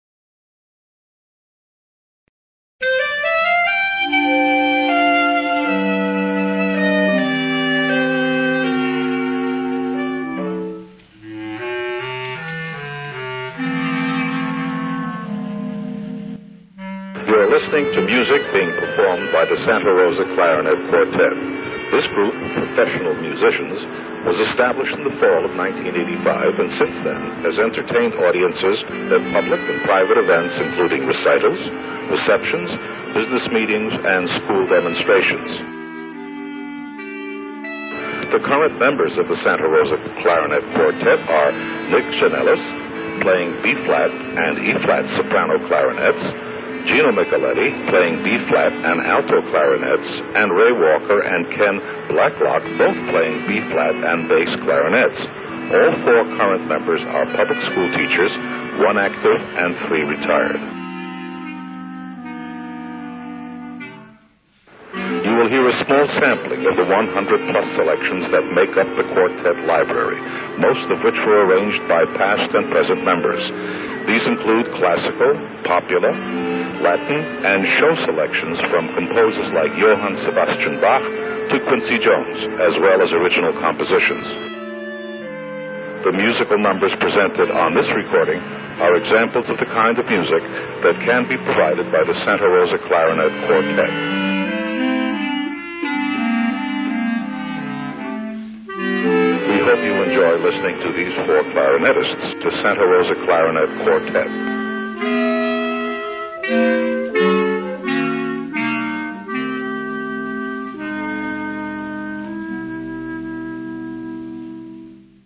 Alto clarinet